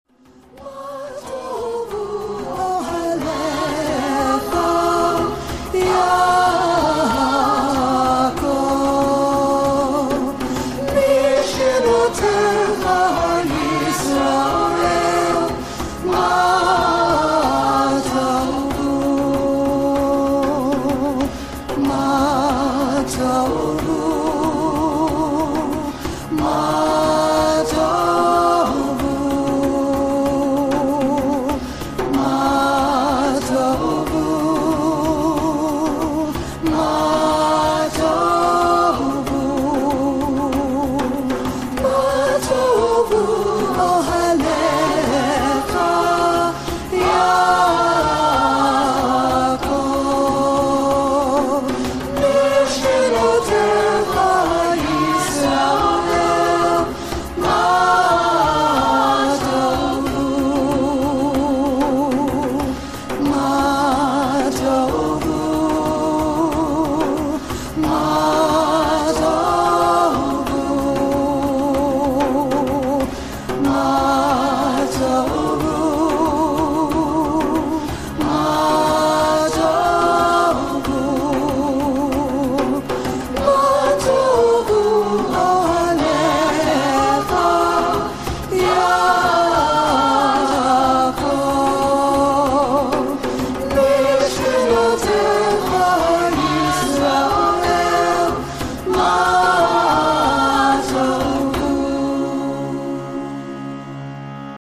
CHANT FOR HONORING JACOB AND ISRAEL